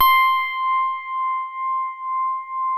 TINE HARD C5.wav